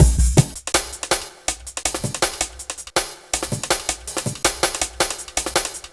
Dinky Break 04-162.wav